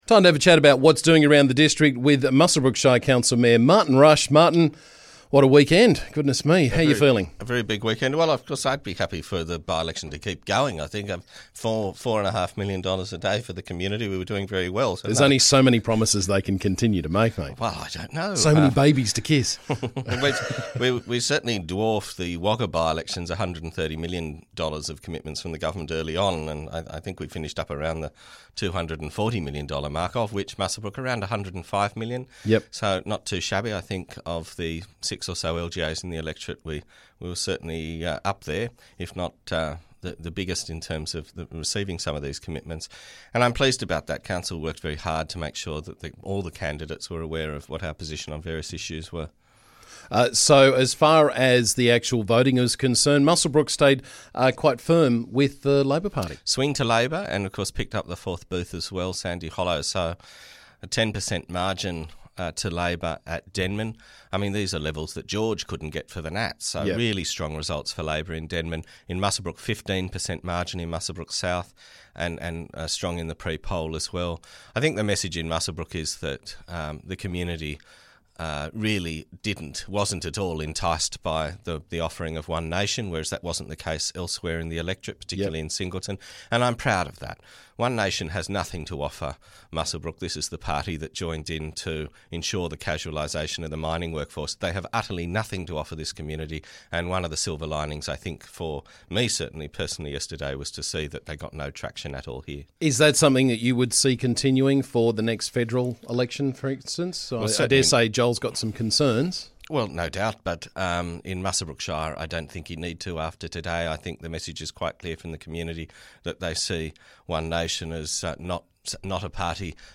Mayor of the Muswellbrook Shire, Martin Rush was on the show to discuss the result of the Upper Hunter by-election.